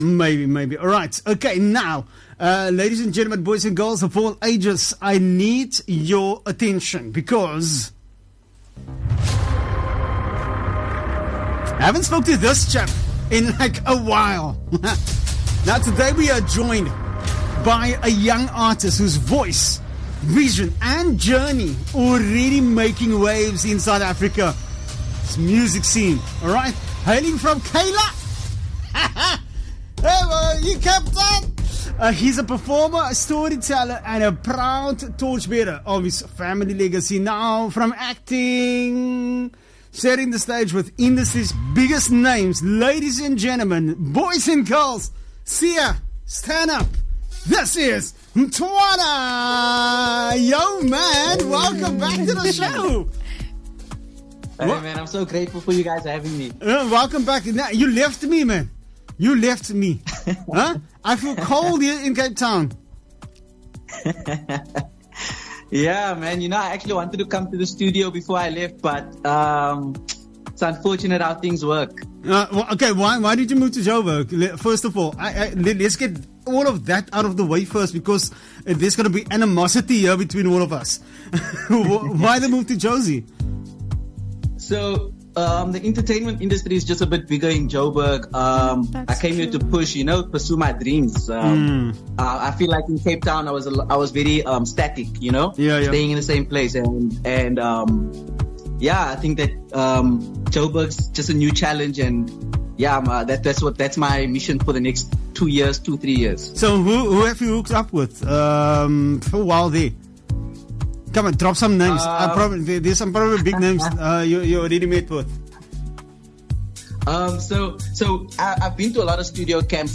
12 Jul Artist Interview